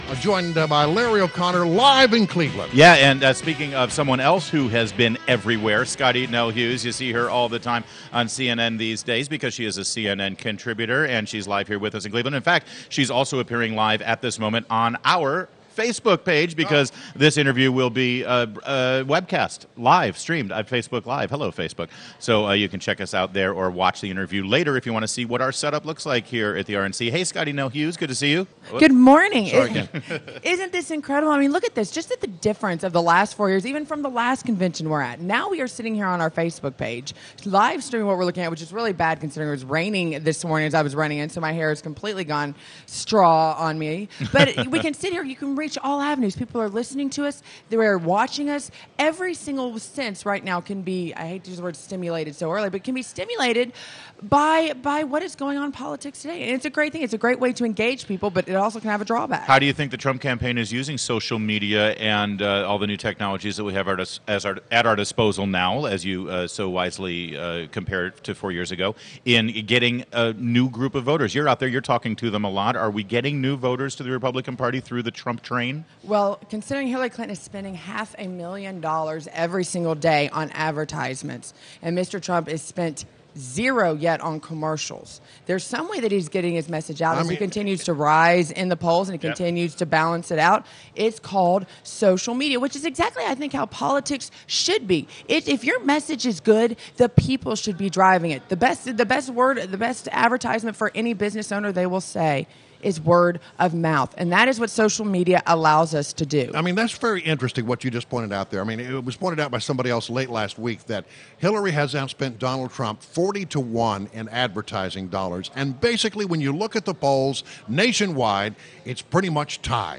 WMAL Interview - Scottie Nell Hughes - 07.18.16